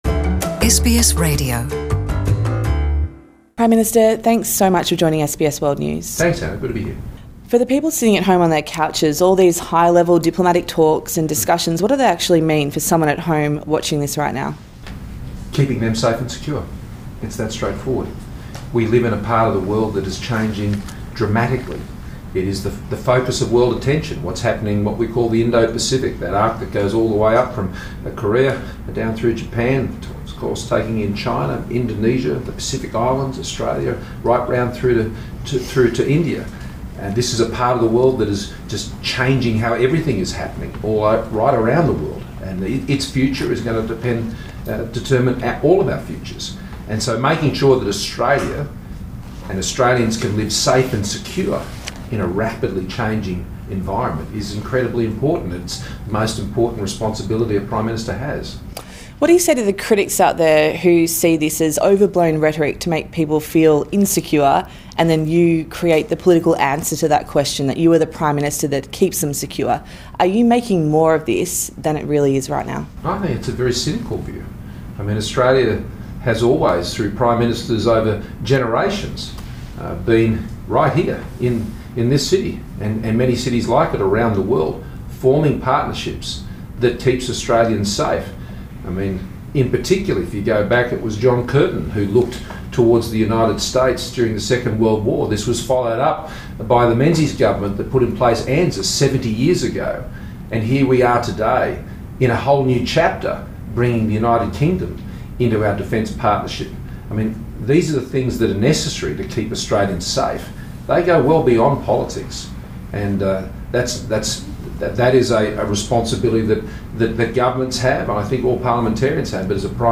PM Scott Morrison speaks to SBS in Washington Source: SBS